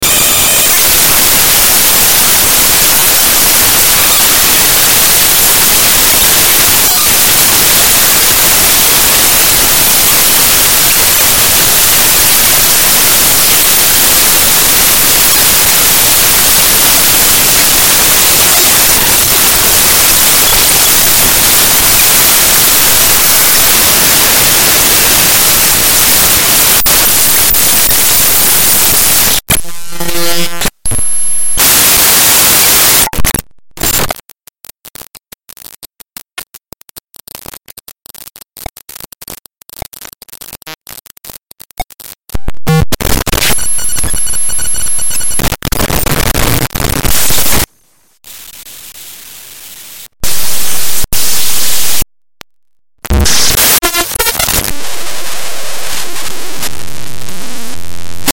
Free Glitch Sound - Bouton d'effet sonore